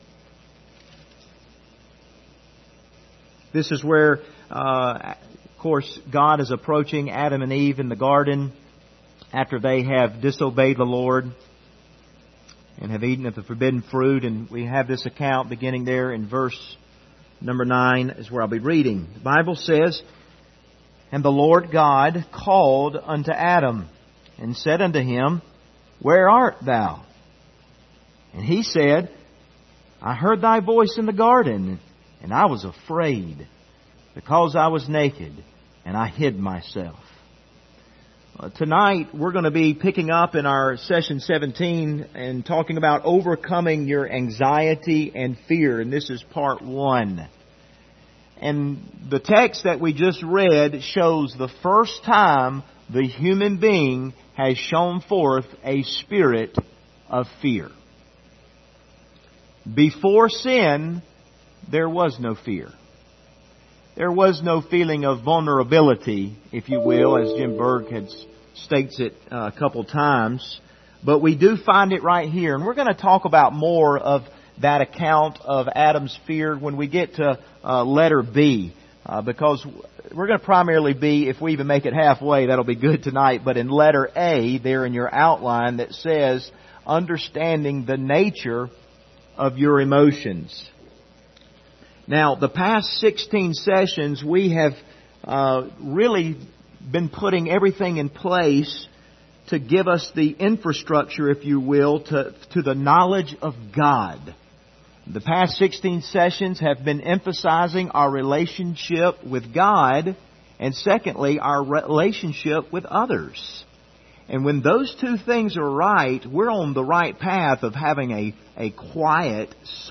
Service Type: Wednesday Evening Topics: anxiety , fear